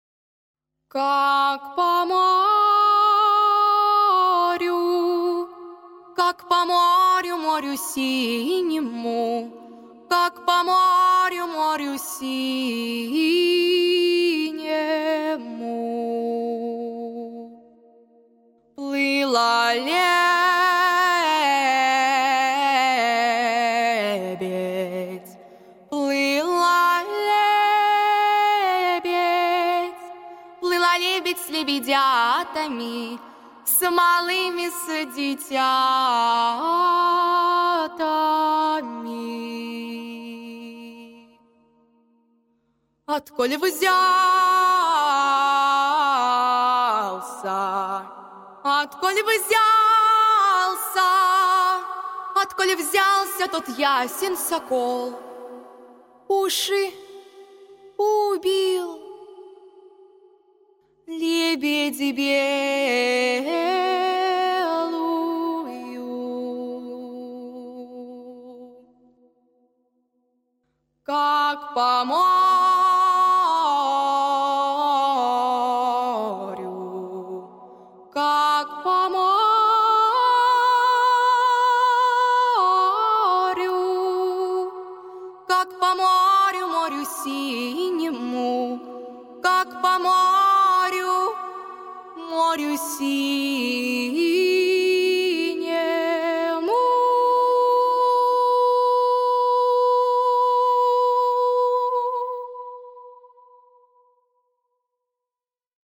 • Категория: Детские песни
акапелла, народный мотив